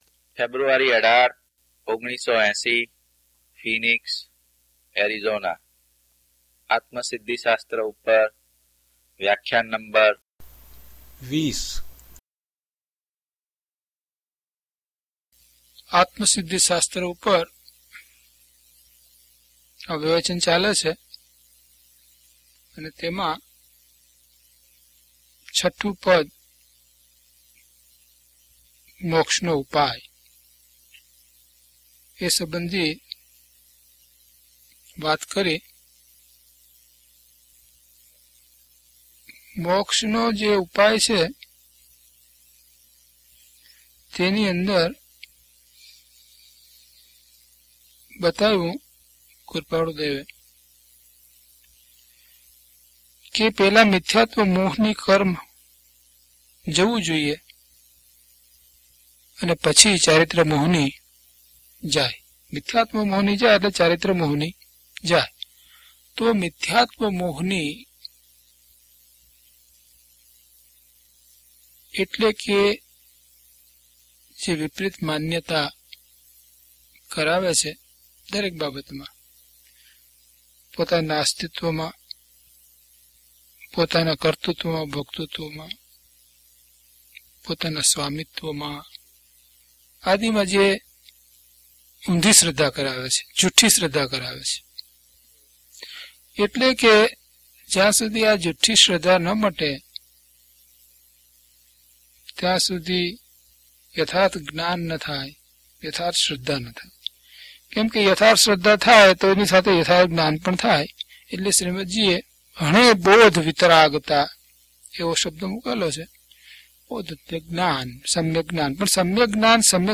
DHP029 Atmasiddhi Vivechan 20 - Pravachan.mp3